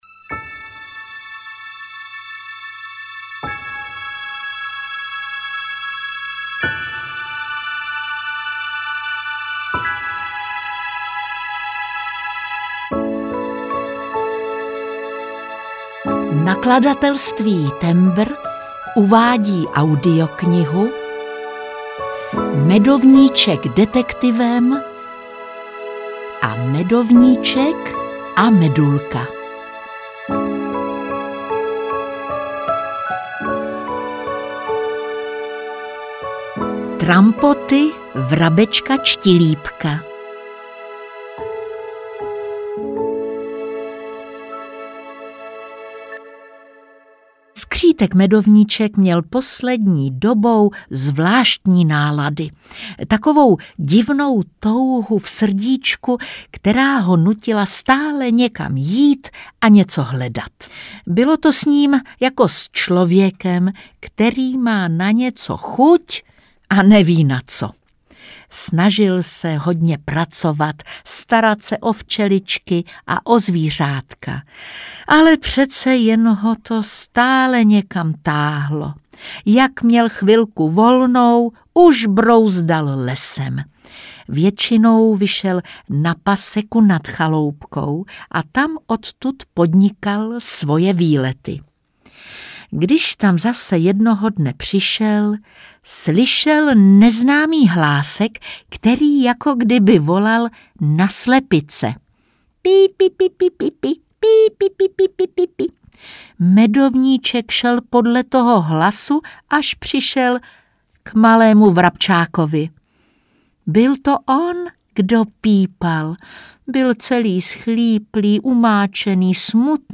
Ukázka z knihy
• InterpretJitka Molavcová